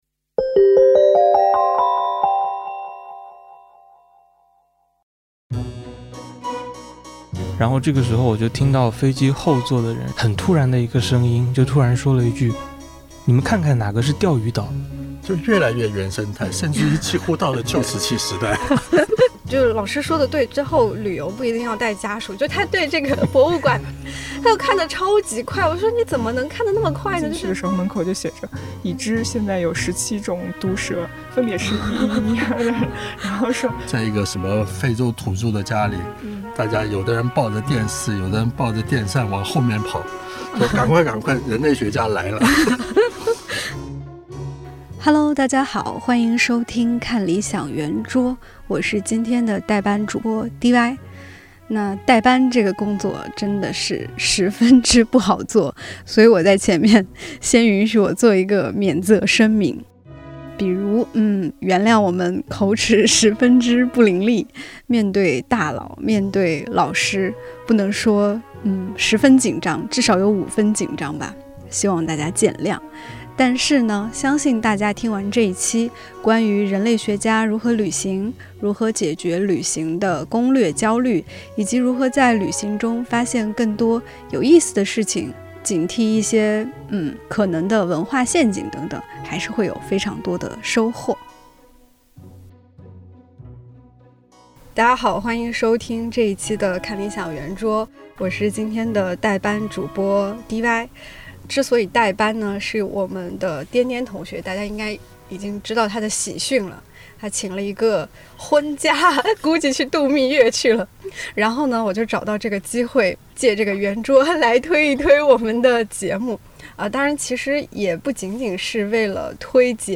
看理想出品，入选“2019 Apple 最佳播客”，每周四更新，编辑和主讲人放飞自我的圆桌聊天，时不时也招呼各个领域的好朋友们来坐一坐，和你一起听见生活更多可能。